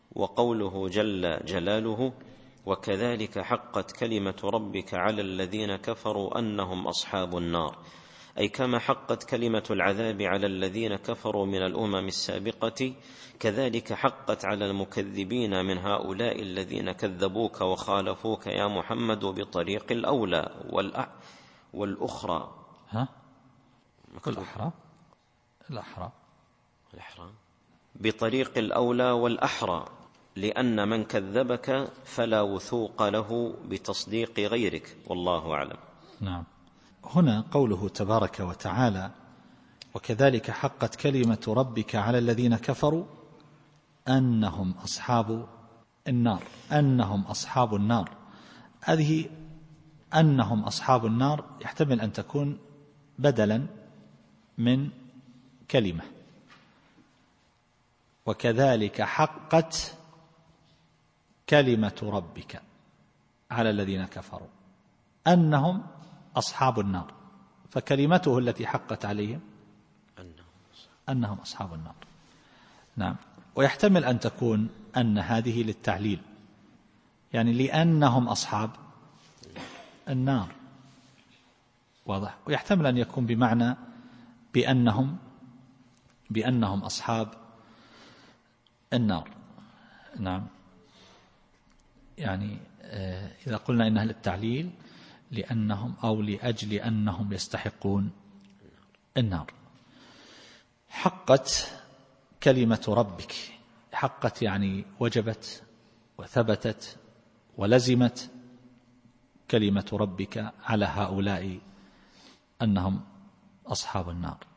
التفسير الصوتي [غافر / 6]